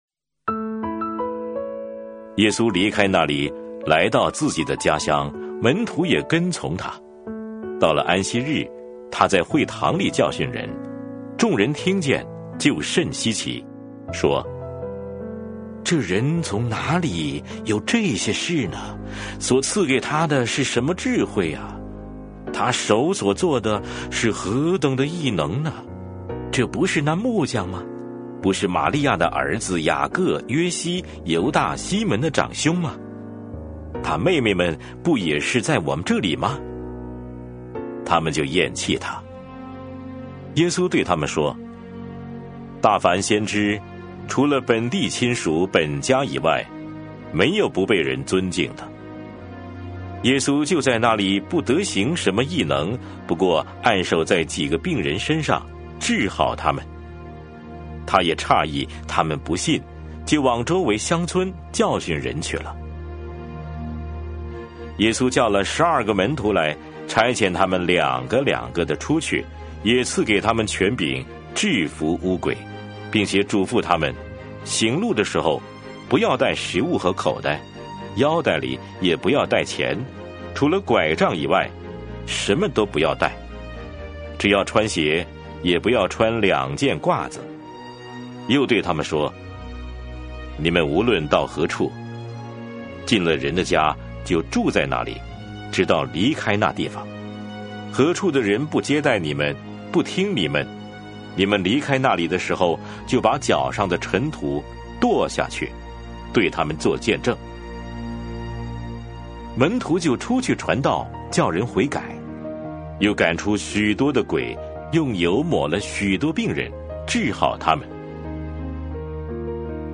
每日读经 | 马可福音6章
以上音频由汉语圣经协会录制